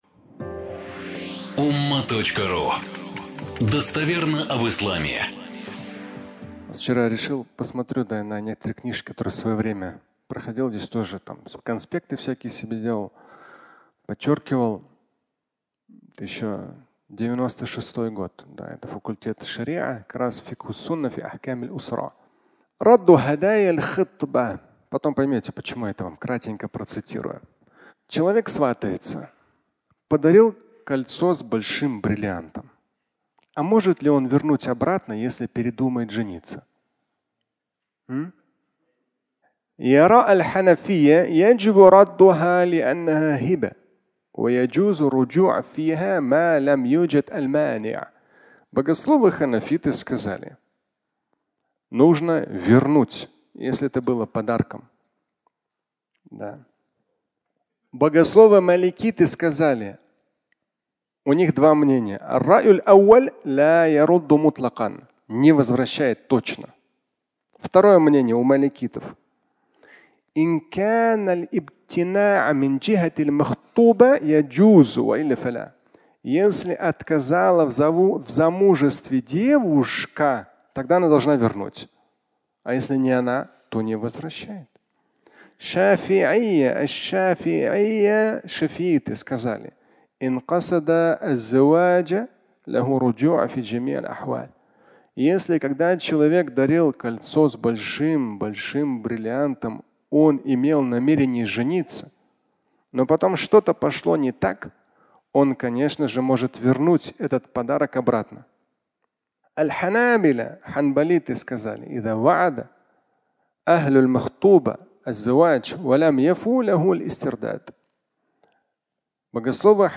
Кольцо с бриллиантом (аудиолекция)